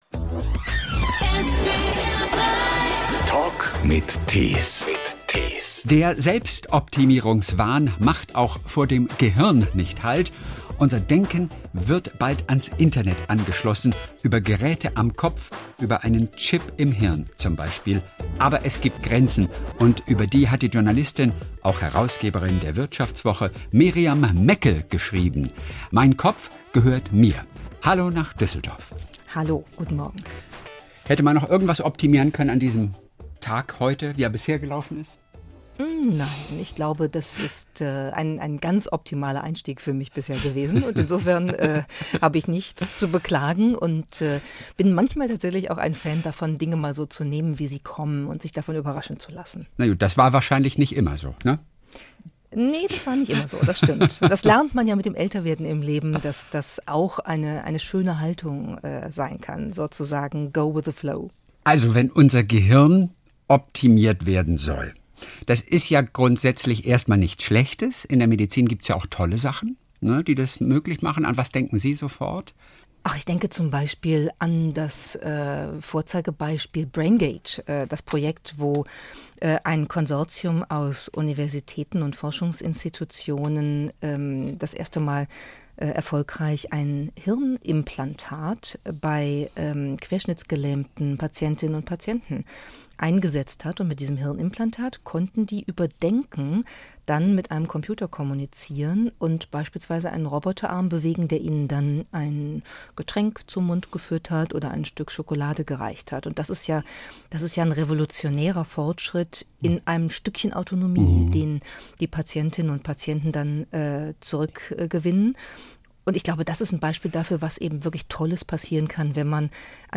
Der Talk in SWR3